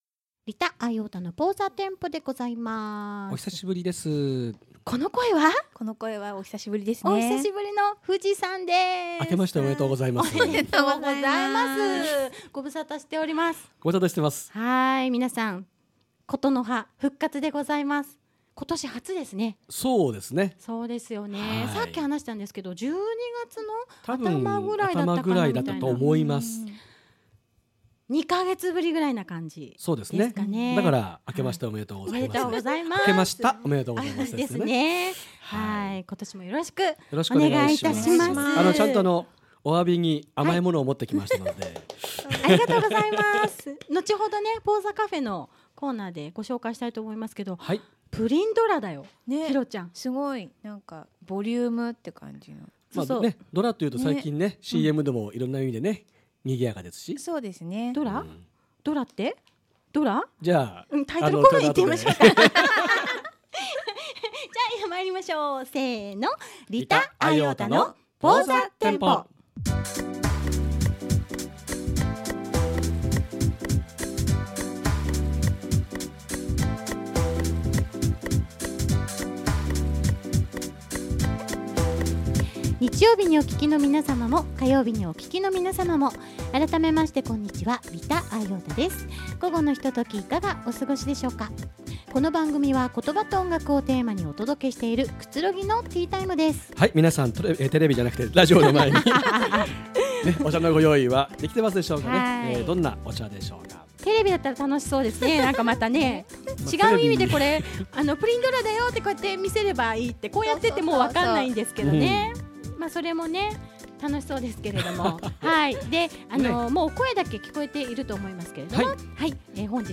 ま〜たお菓子だらけのスタジオ。
全くかみあっていないトークがポーザらいくいい感じ〜！